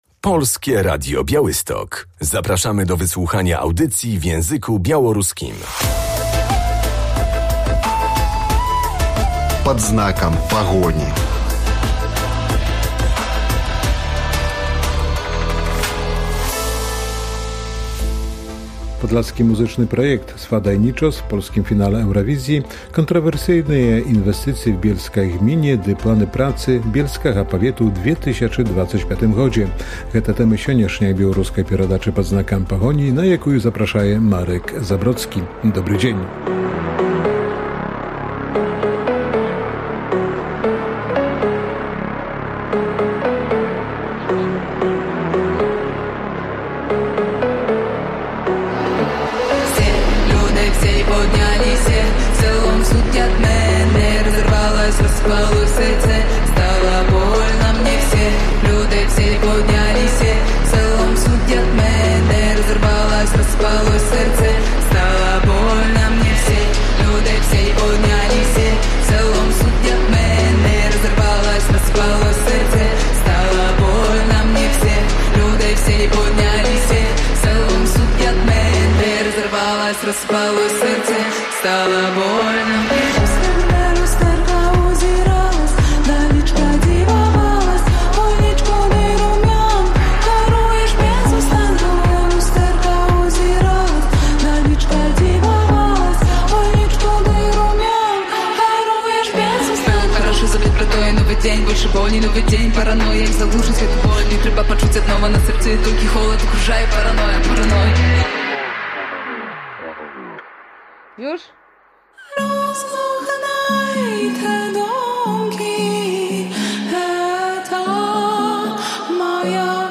Czy w wiejskiej gminie Bielsk Podlaski powstanie przemysłowa ferma kurza i farma wiatrowa licząca 30 wiatraków – takie pytanie zadajemy wójtowi gminy – Walentemu Koryckiemu.